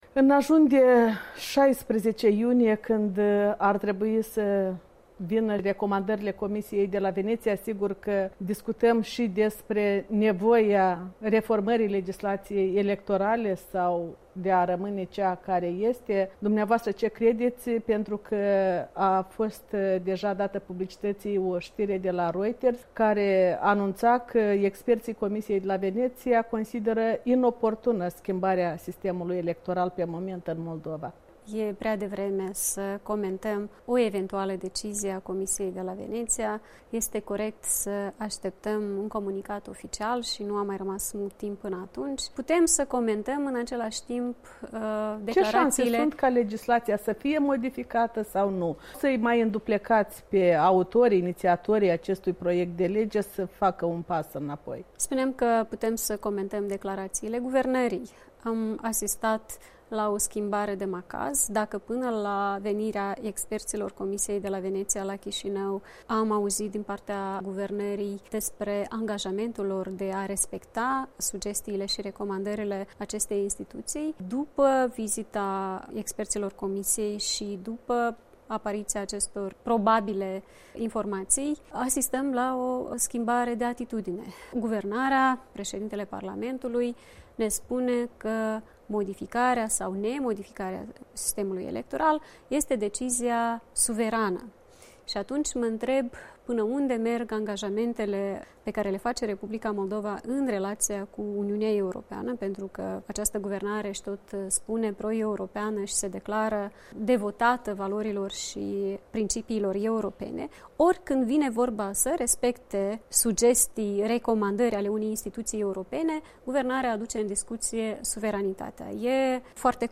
Un interviu cu lidera Partidului „Acțiune și Solidaritate”.
Maia Sandu, lidera PAS răspunde întrebărilor Europei Libere